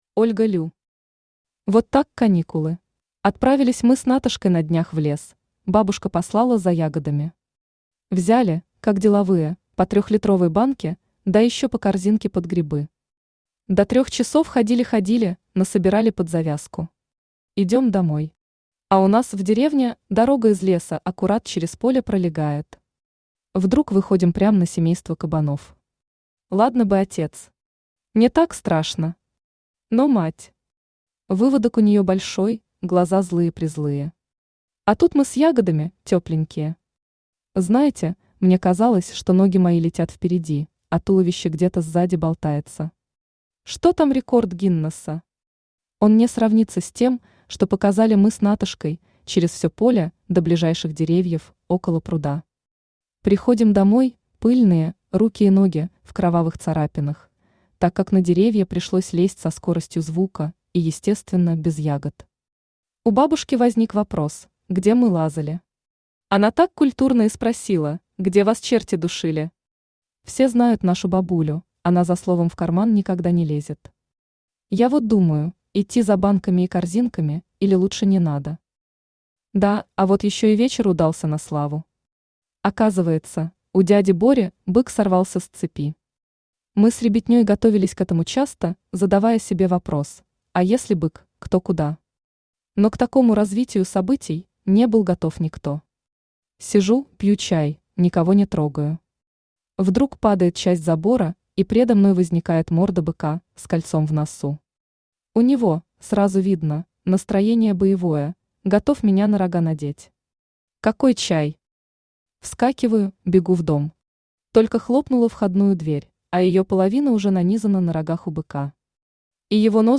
Аудиокнига Вот так каникулы | Библиотека аудиокниг
Aудиокнига Вот так каникулы Автор Ольга Лю Читает аудиокнигу Авточтец ЛитРес.